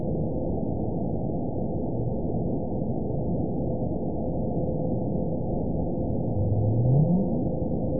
event 917798 date 04/17/23 time 00:31:39 GMT (2 years ago) score 9.58 location TSS-AB01 detected by nrw target species NRW annotations +NRW Spectrogram: Frequency (kHz) vs. Time (s) audio not available .wav